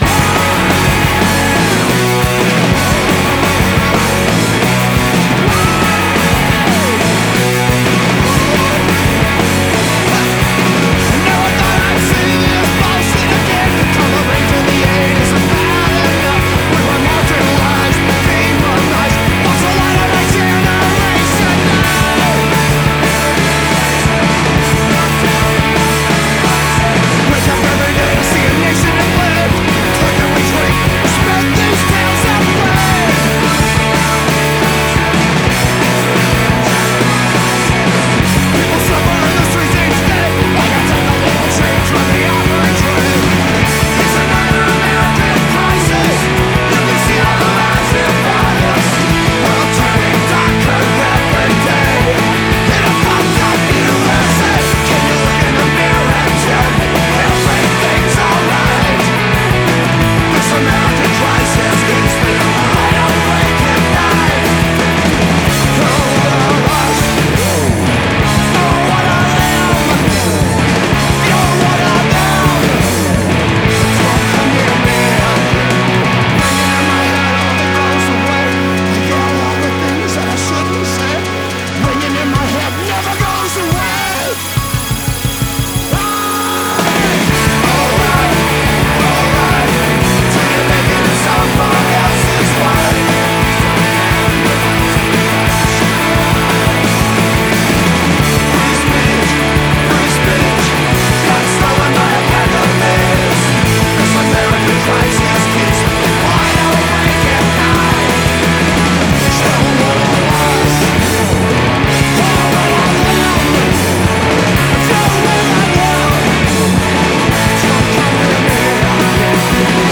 la basse nerveuse
la batterie en béton armé